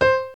SAMPLES : Piano
piano nē 4
piano4.mp3